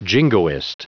Prononciation du mot jingoist en anglais (fichier audio)
Prononciation du mot : jingoist